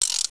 shuffle.wav